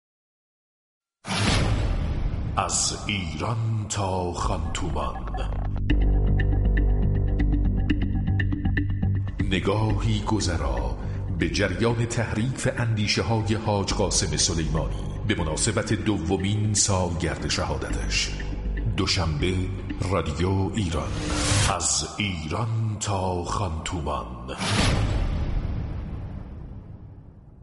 در این مستند روایی چهره های شاخصی از شهید حاج قاسم سلیمانی می گویند كه می توان به سرلشكر جعفری و امیر عبدالهیان اشاره كرد.